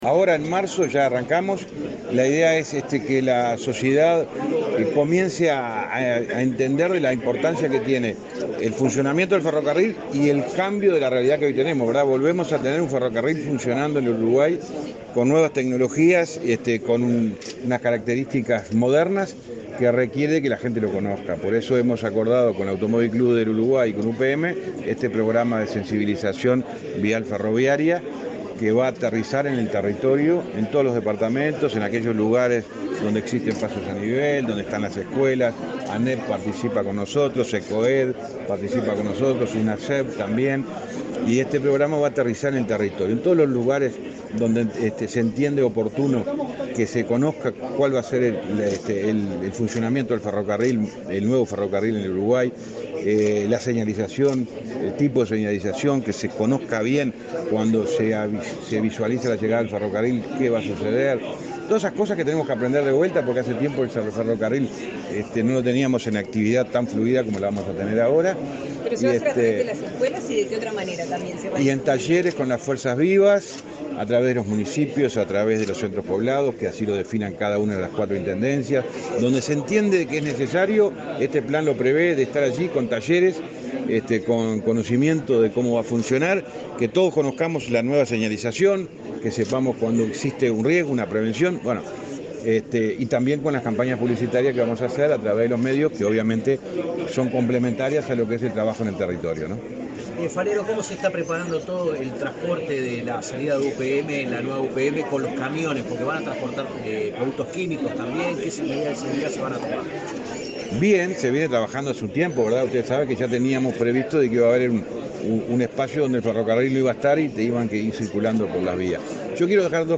Declaraciones del ministro de Transporte, José Luis Falero
El ministro de Transporte, José Luis Falero, dialogó con la prensa luego de participar de un acto en la sede de su cartera, en el que se presentó el